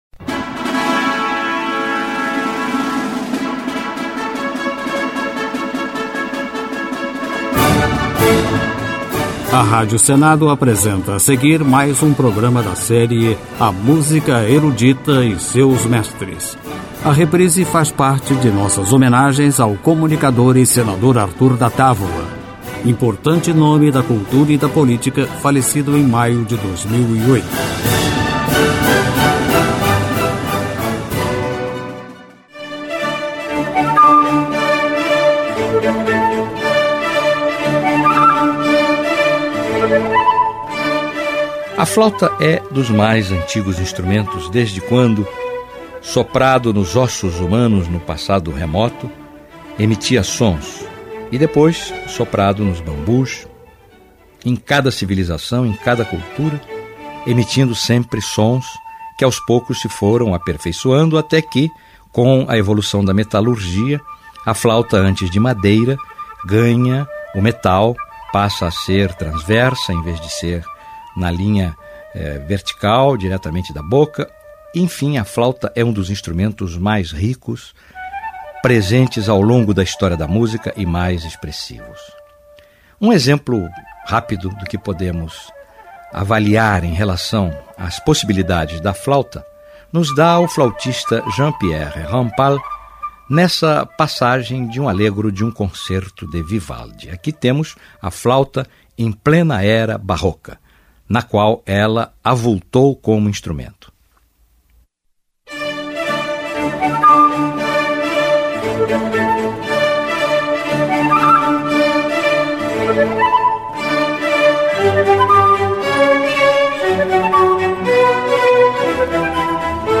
Música Erudita
Sonoridades da flauta